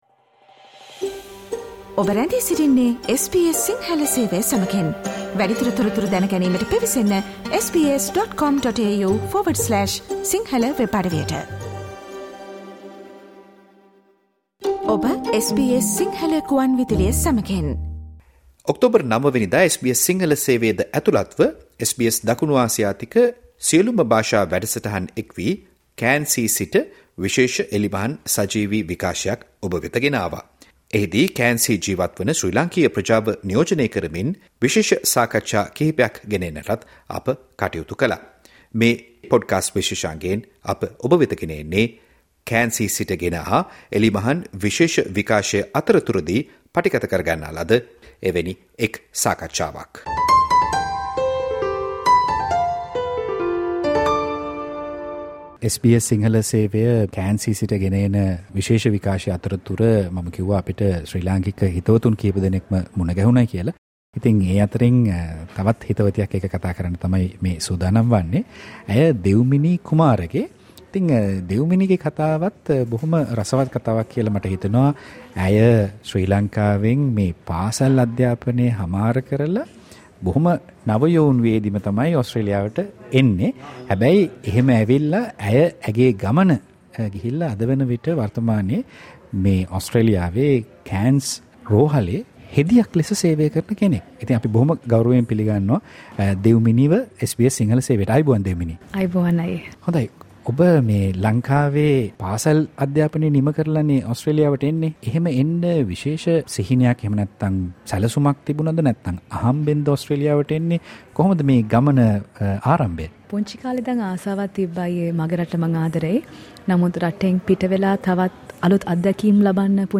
SBS සිංහල වැඩසටහන